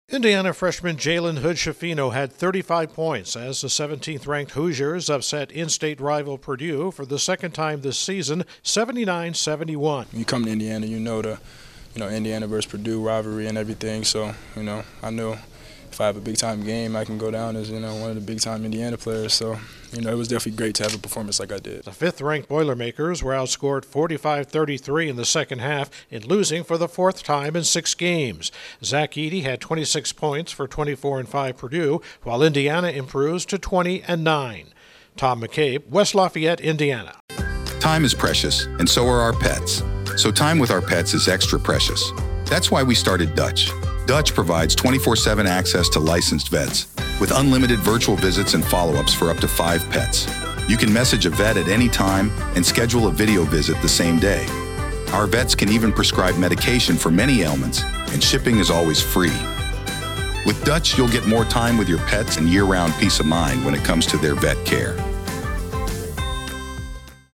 A first-year Hoosier puts his stamp on the Indiana-Purdue rivaly. Correspondent